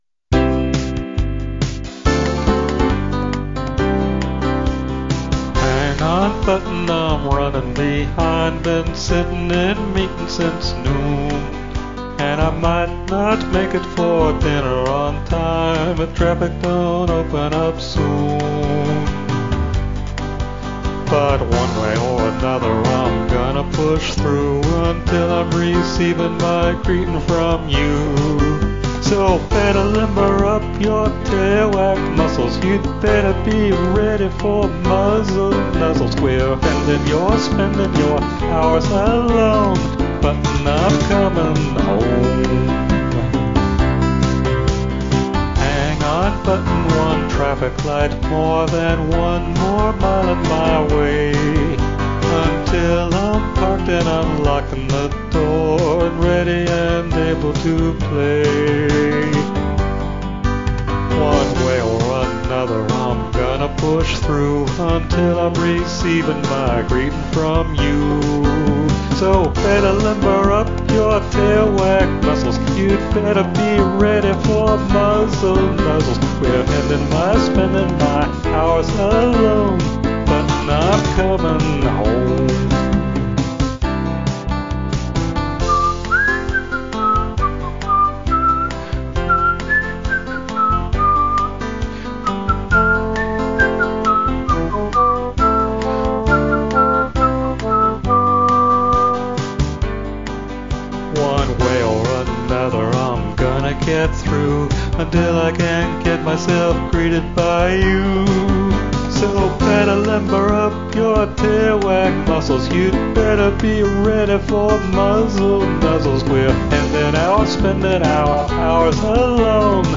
pop, male or female voice